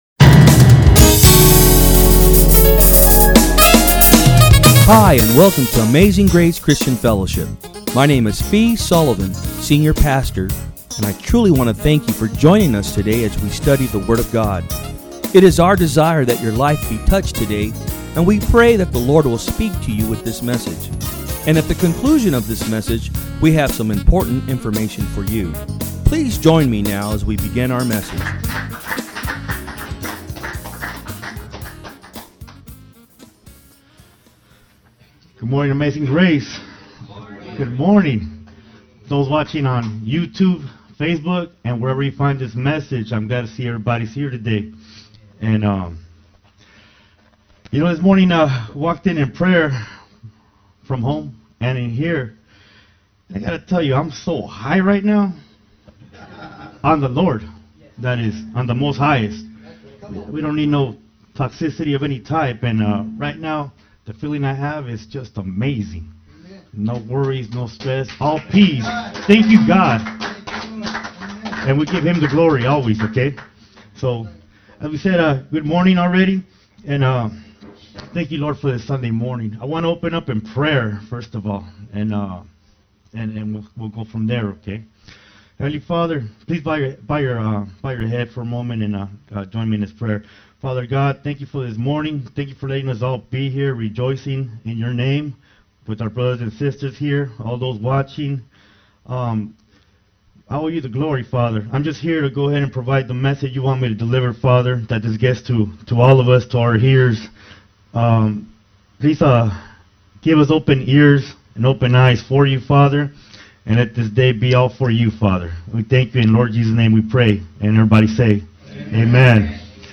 Sermons
From Service: "Sunday Am"